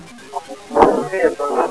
To me....it sounds like a guy saying...."Thats what it is....uh-huh."